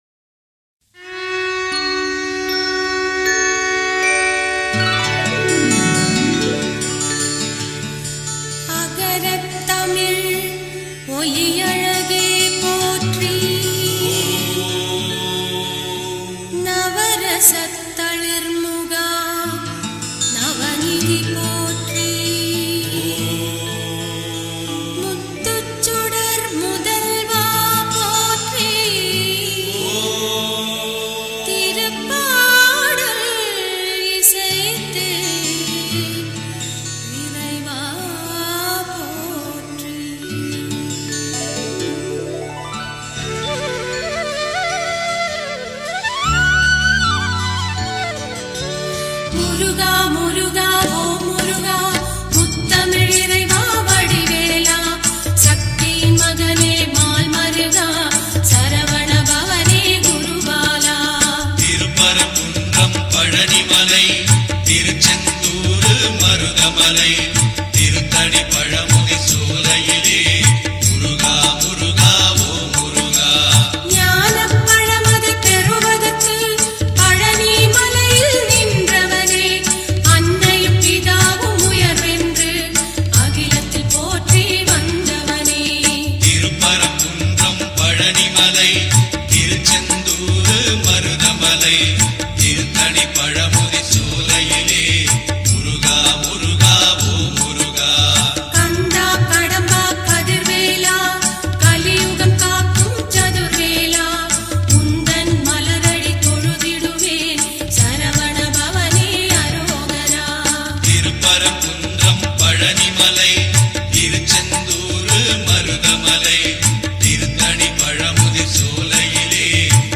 devotional album songs